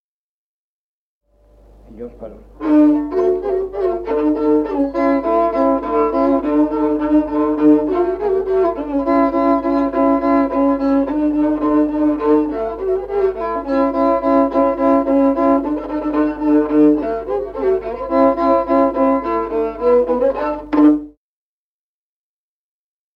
Музыкальный фольклор села Мишковка «Подушечка», партия 2-й скрипки.